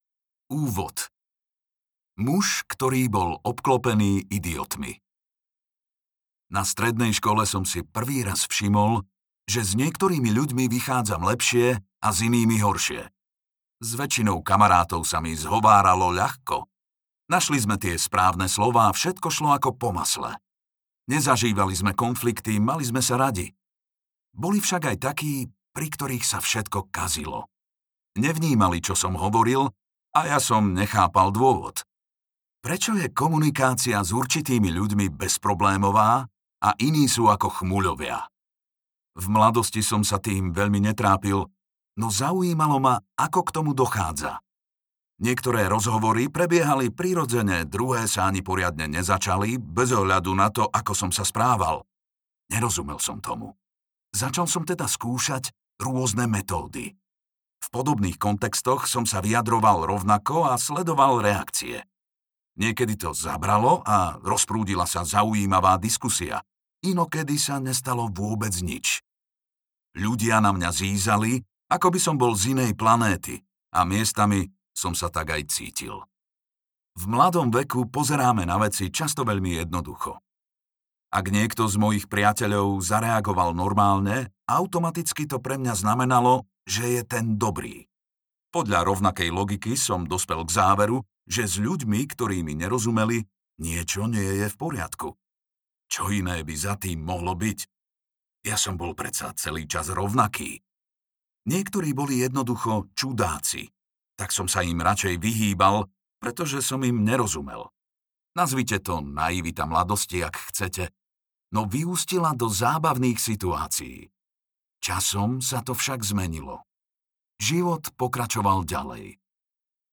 audiokniha
Čte: Martin Kaprálik